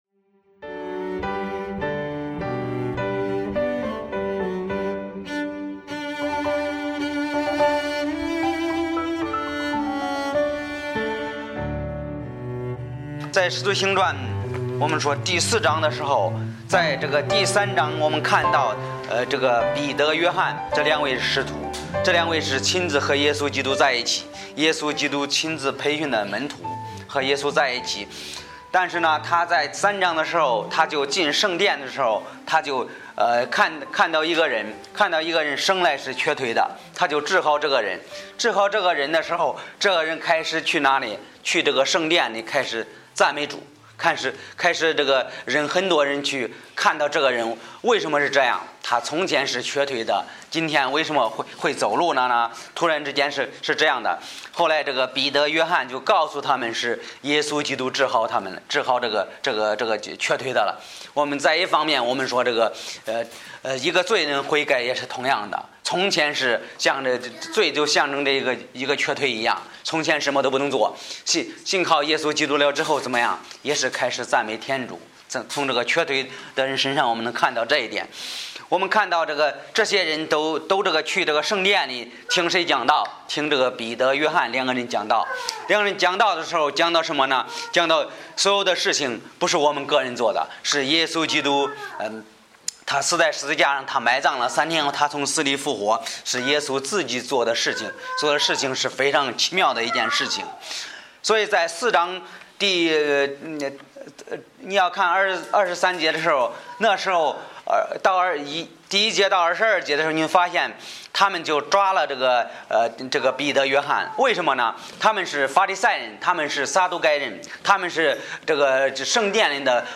Bible Text: 使徒行传 4:23-37 | 讲道者